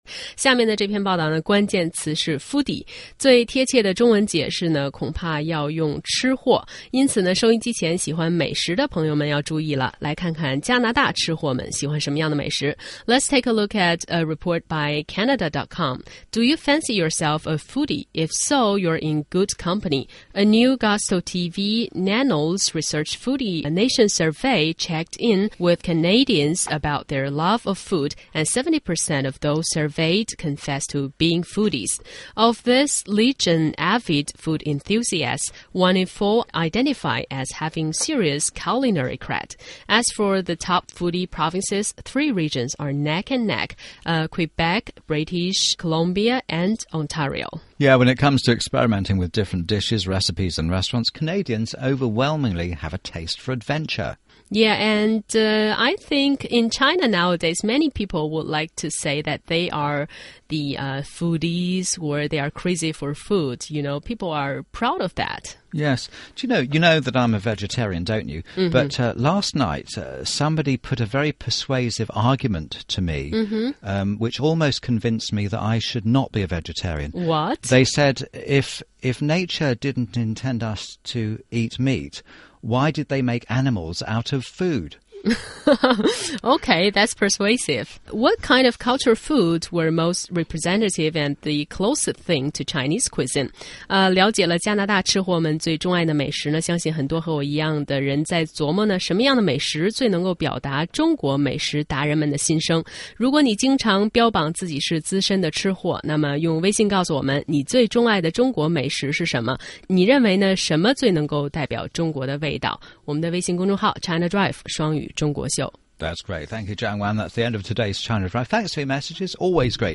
中英双语的音频，能够帮助提高英语学习者的英语听说水平，中外主持人的地道发音，是可供模仿的最好的英语学习材料,可以帮助英语学习者在轻松娱乐的氛围中逐渐提高英语学习水平。